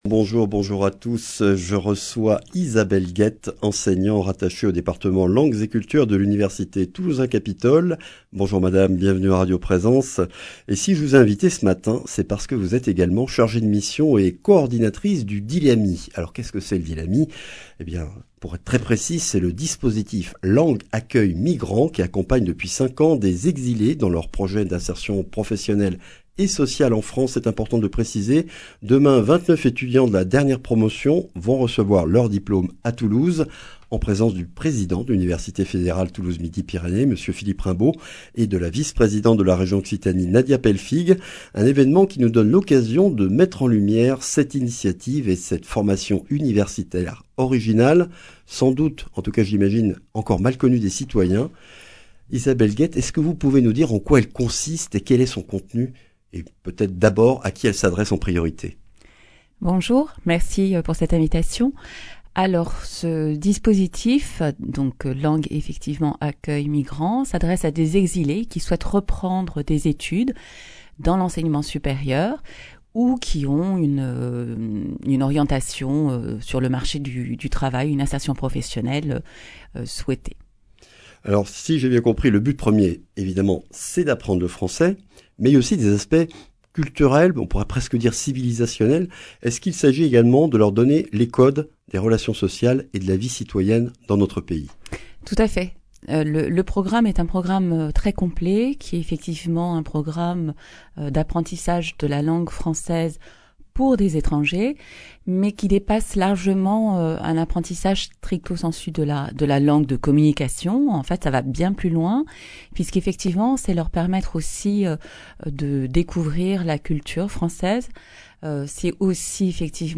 Accueil \ Emissions \ Information \ Régionale \ Le grand entretien \ Le DILAMI, dispositif langues accueil migrants de l’université fédérale (…)